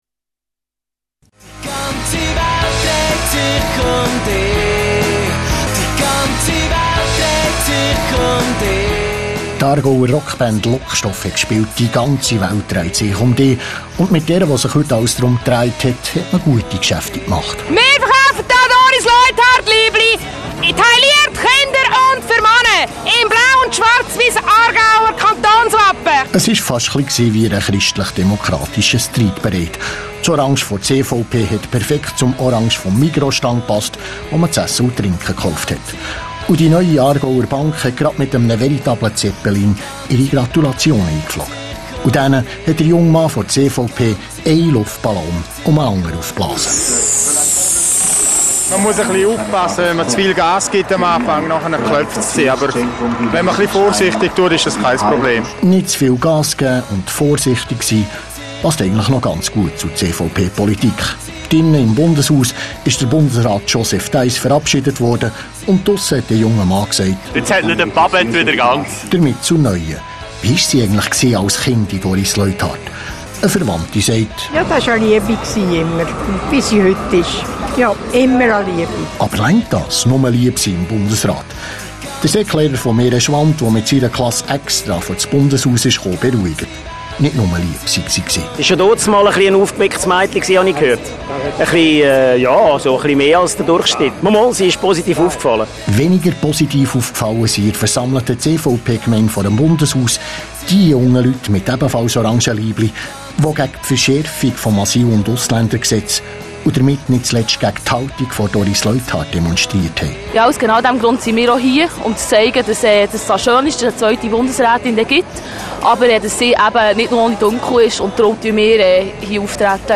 Auch vor dem Bundeshaus in Bern – auf dem Bundesplatz – war am Wahlmorgen einiges los.